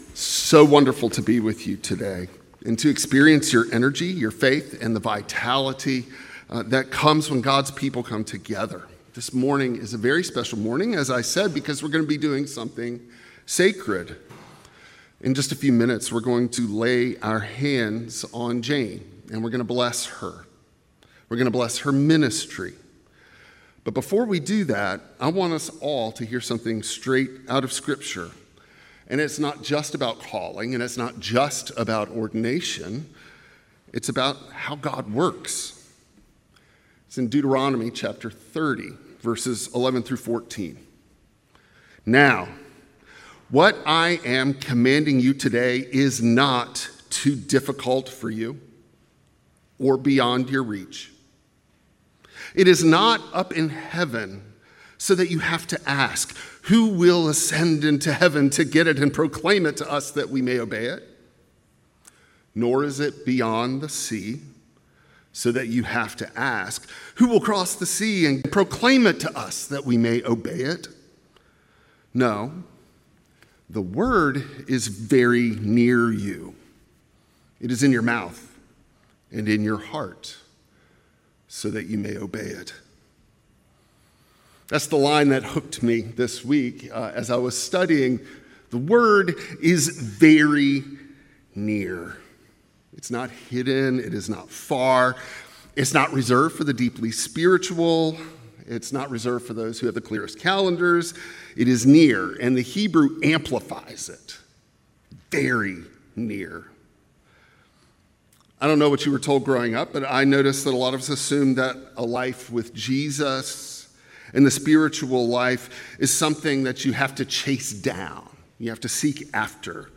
Passage: Deuteronomy 30:11-14, Luke 10:25–37 Service Type: Traditional Service